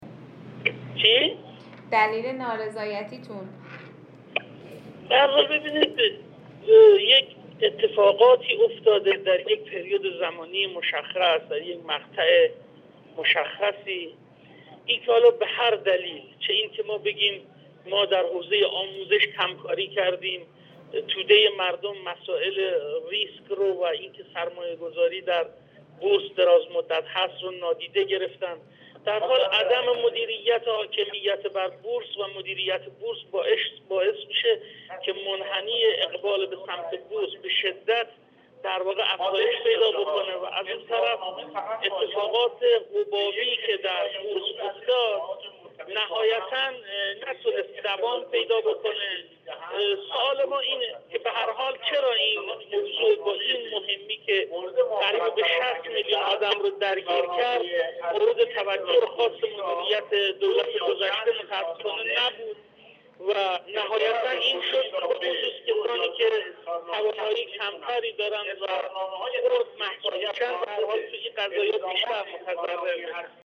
بهروز محبی نجم آبادی، عضو کمیسیون برنامه و بودجه در گفت‌وگو با بورس‌نیوز، اظهار کرد: در یک بازه زمانی مشخص در سال ۹۹ با وقوع اتفاقات و کم‌کاری‌هایی که به ضرر بازار‌سرمایه در حوزه آموزشی صورت گرفت، همزمان مسائل پر ریسک و مربوط به سرمایه‌گذاری بلندمدت مورد توجه قرار نگرفت.